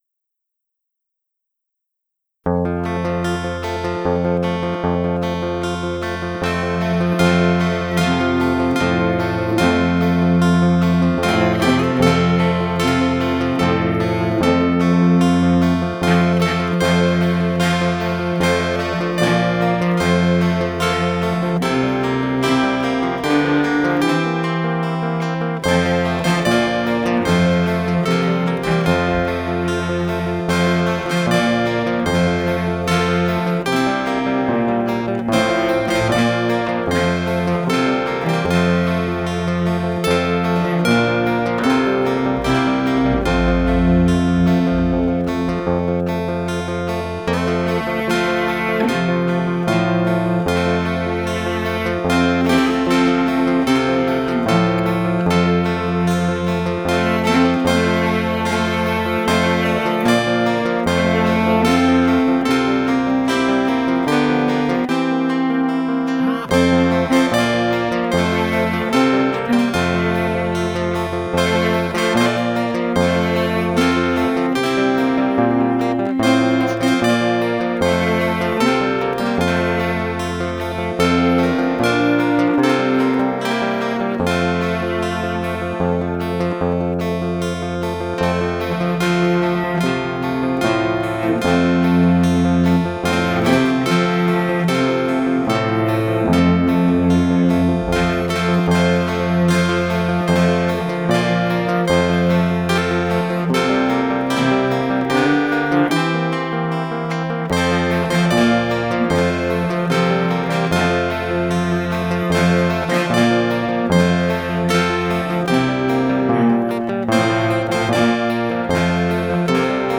Hinos Ccb tocados no Violão